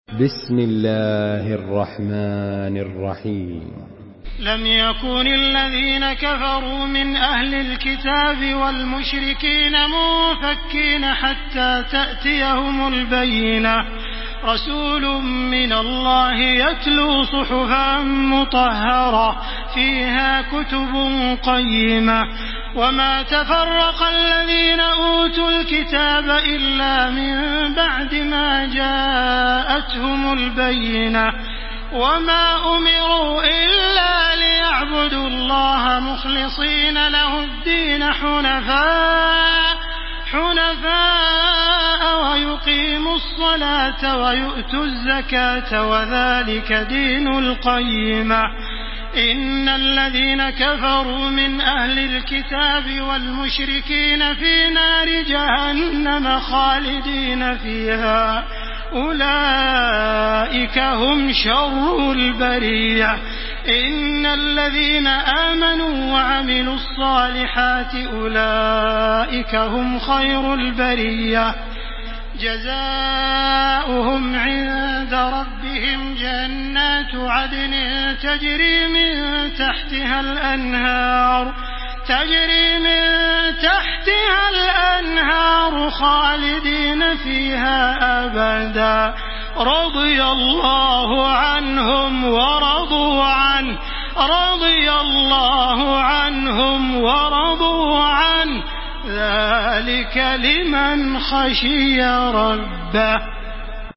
Surah البينه MP3 in the Voice of تراويح الحرم المكي 1434 in حفص Narration
مرتل